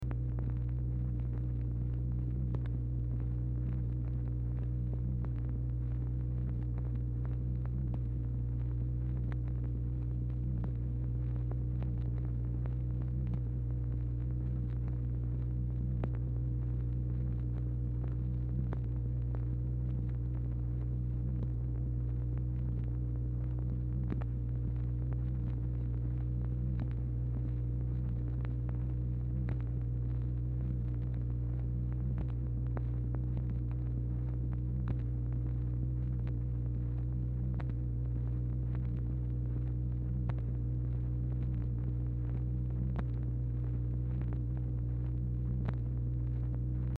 Telephone conversation # 1135, sound recording, MACHINE NOISE, 1/1/1964, time unknown | Discover LBJ
Format Dictation belt
LBJ Ranch, near Stonewall, Texas